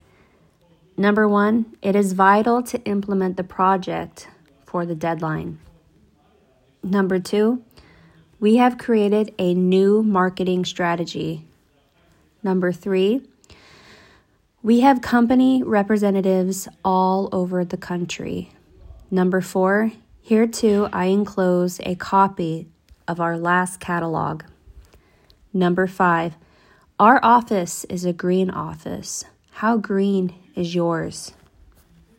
amerikai anyanyelvi